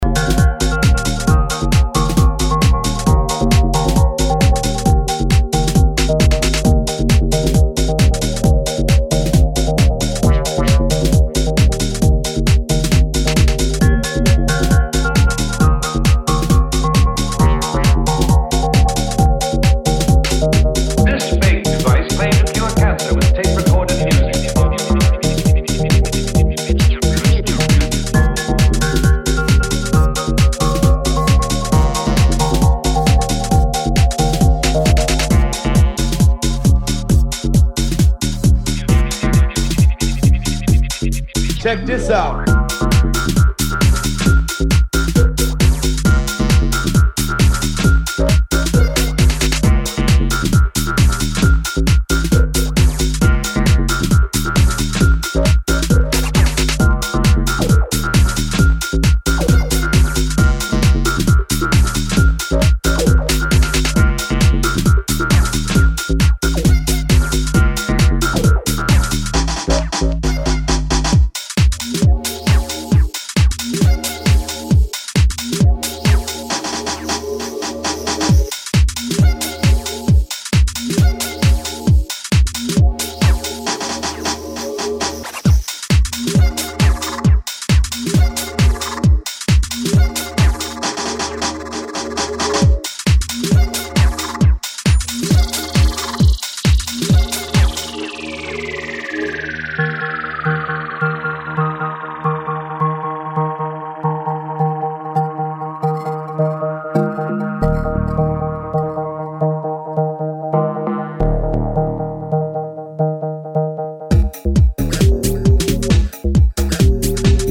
a splash of fun in a house key